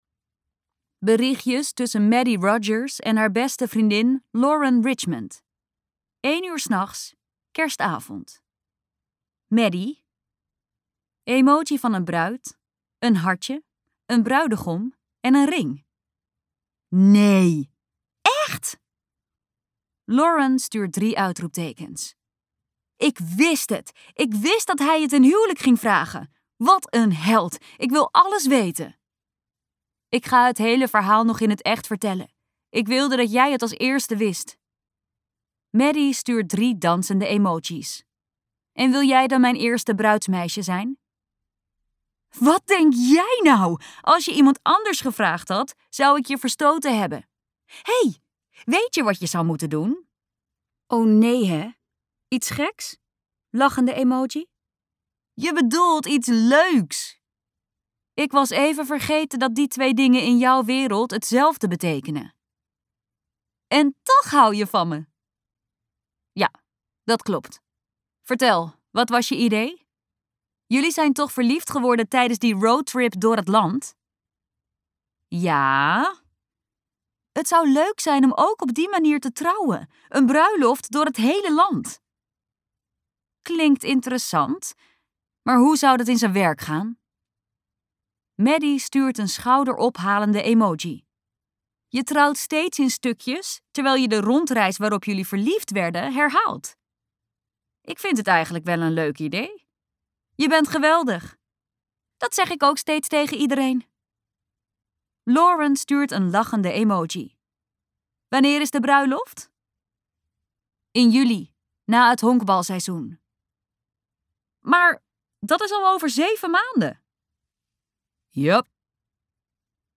KokBoekencentrum | Knettergek van jou luisterboek